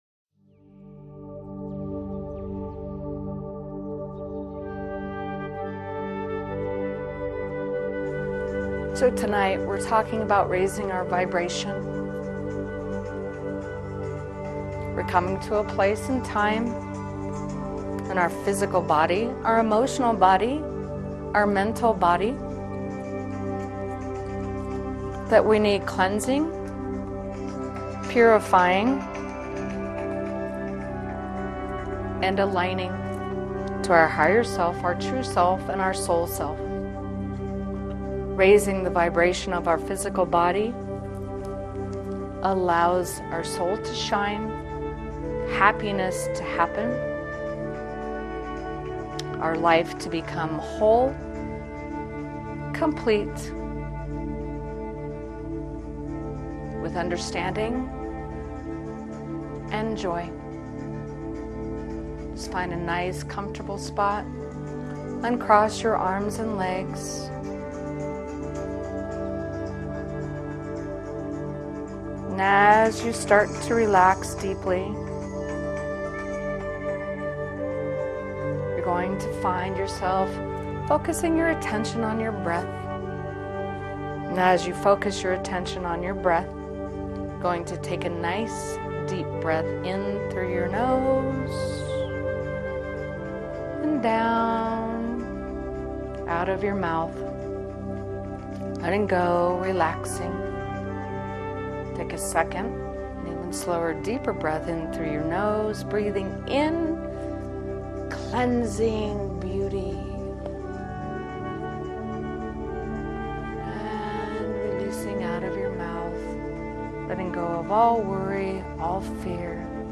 This weeks podcast is a meditation.